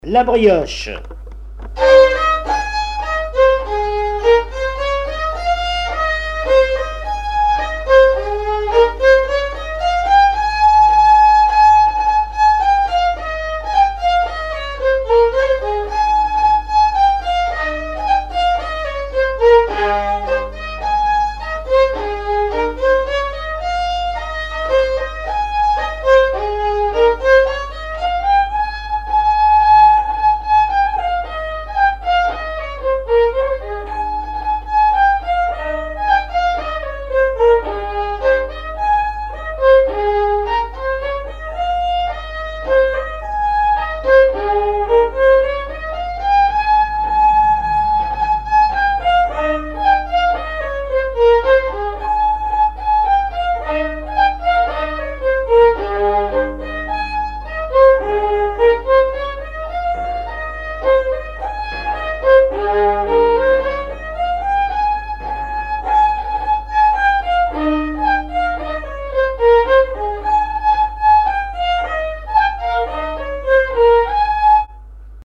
Chants brefs - A danser
branle : danse de la brioche
recherche de répertoire de violon pour le groupe folklorique
Pièce musicale inédite